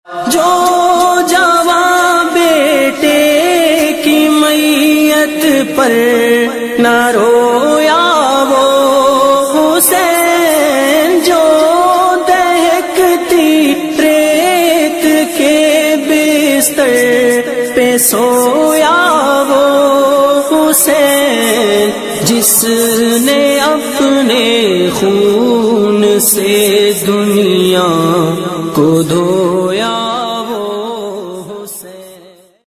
Islamic Ringtones